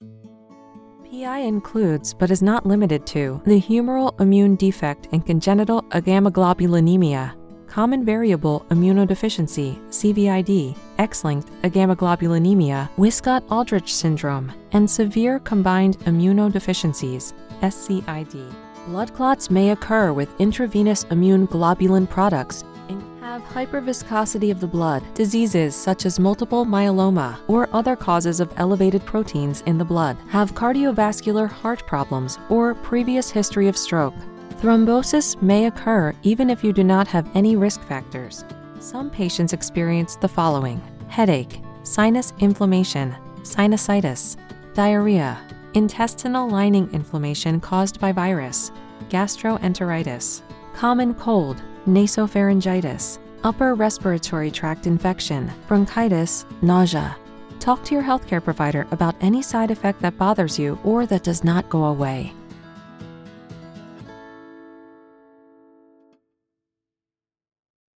From commercials and explainer videos to e-learning, promos, and narration, I offer a warm, engaging sound designed to connect with your audience.